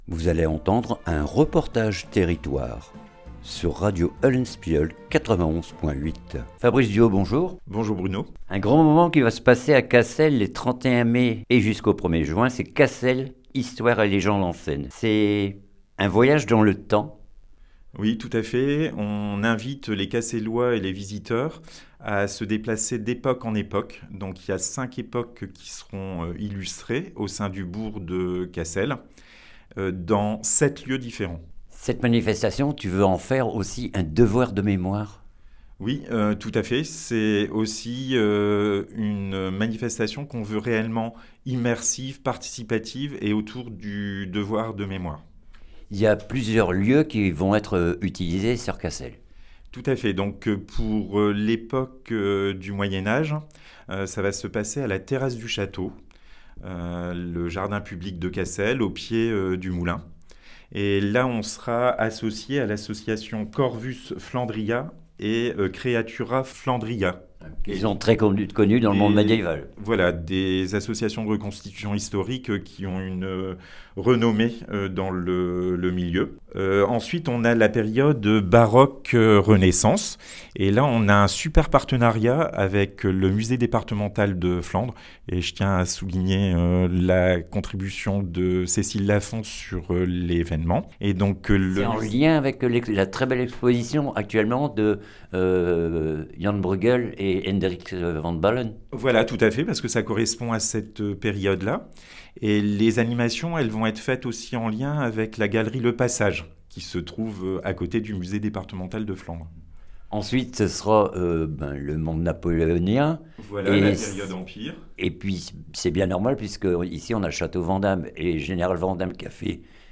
REPORTAGE TERRITOIRE HISTOIRES & LEGENDES EN SCENE CASSEL